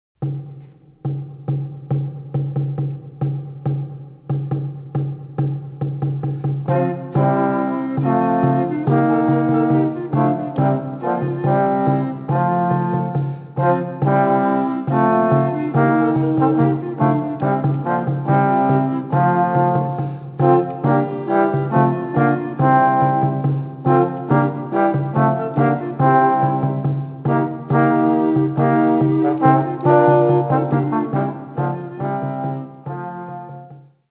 recorders, shawm, crumhorns, curtal
recorders, crumhorns, chalumeaux
curtals, crumhorn, percussion
violin, percussion